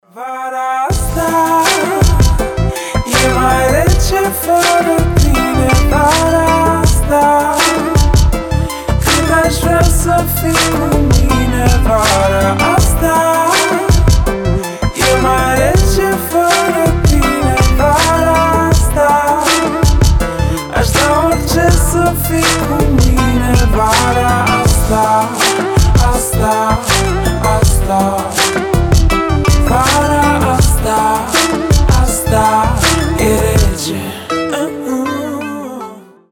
• Качество: 320, Stereo
спокойные
медленные
ремиксы